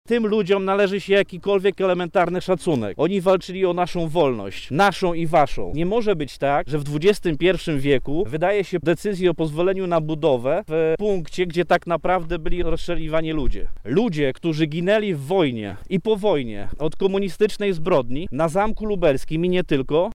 Tych kości nie przynosi wiatr– mówi radny PiS Piotr Breś: